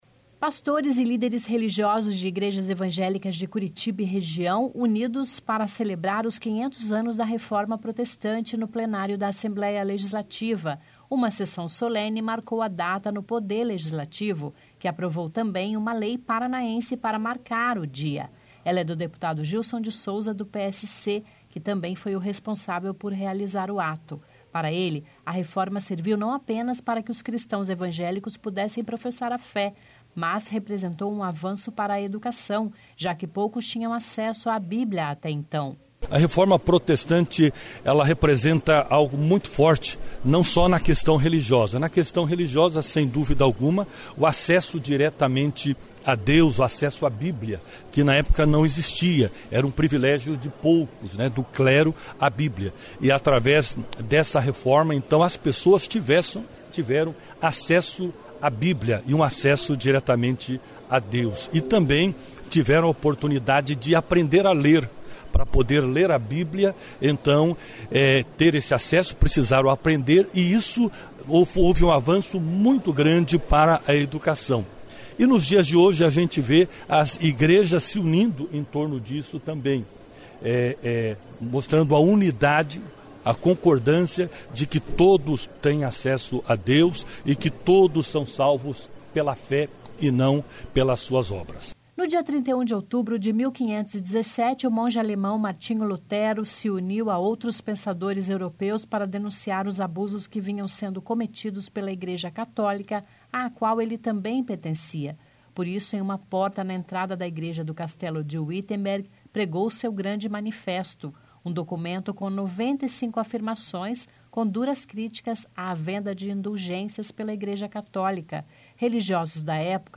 500 anos da Reforma Protestante são celebrados no Plenário da Alep
Uma sessão solene marcou a data no Poder Legislativo, que aprovou também uma lei paranaense para marcar a...